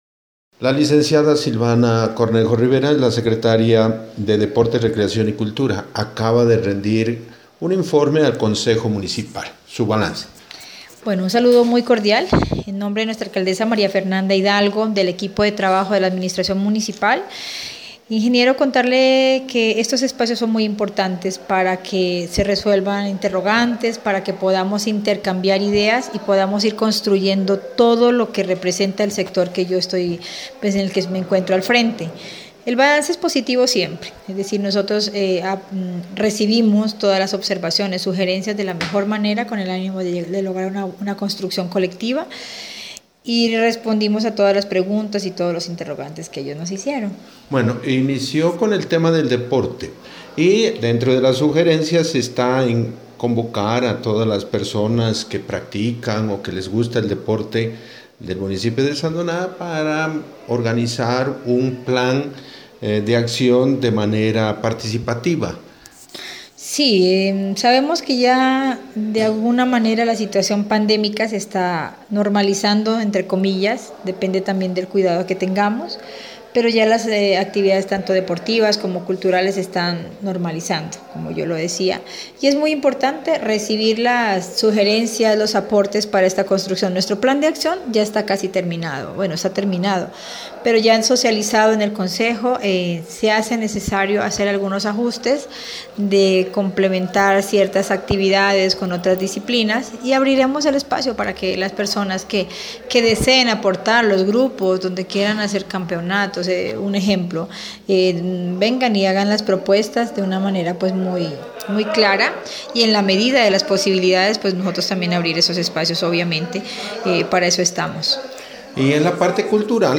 Entrevista con la secretaria de deporte, recreación y cultura Silvana Cornejo Rivera: